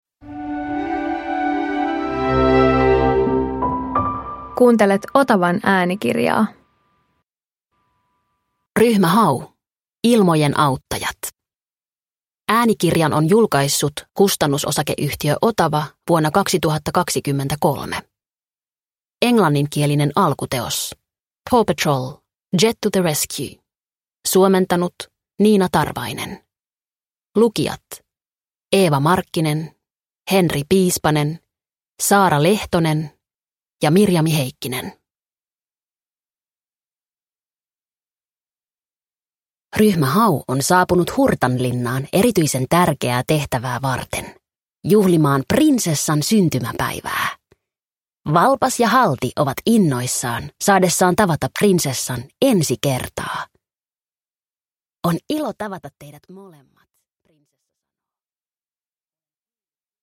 Ryhmä Hau - Ilmojen auttajat – Ljudbok – Laddas ner